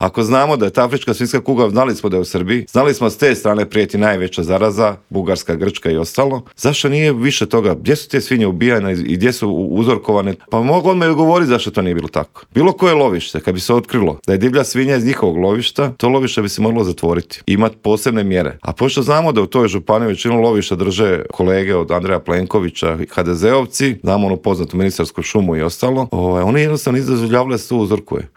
ZAGREB - U Intervjuu Media servisa gostovao je Mario Radić iz Domovinskog pokreta koji se osvrnuo na optužbe premijera Andreja Plenkovića da iza prosvjeda svinjogojaca stoji upravo njegova stranka, otkrio nam s kim bi DP mogao koalirati nakon parlamentarnih izbora, a s kim nikako i za kraj rezimirao 2023. godinu koja lagano ide kraju.